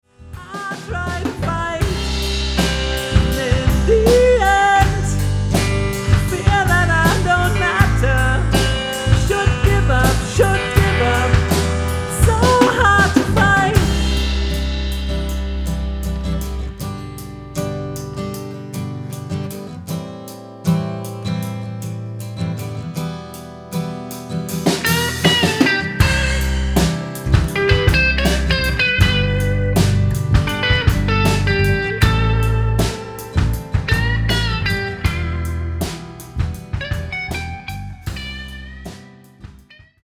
Zur Auflockerung...Ich hatte hier mal zwei relativ aktuelle Schnipsel von 2 OneMic Recordings, vielleicht interessiert es ja.